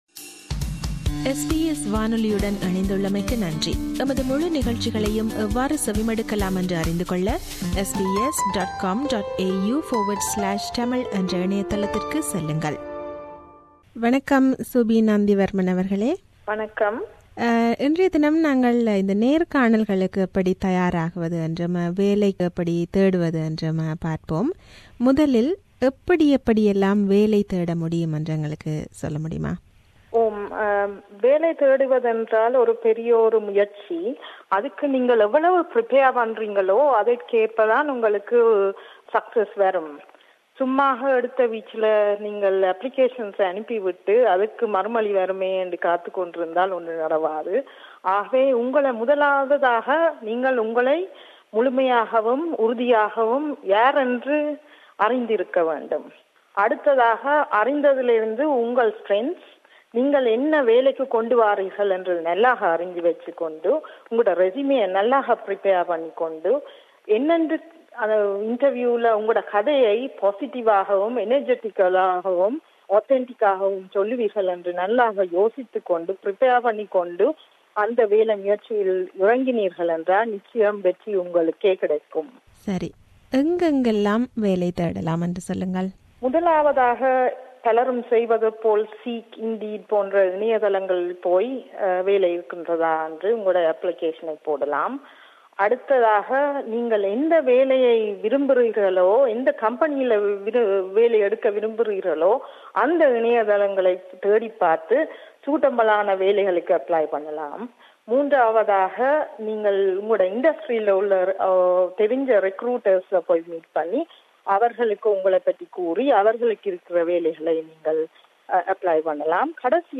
This interview provides tips and advice on preparing for and attending interviews: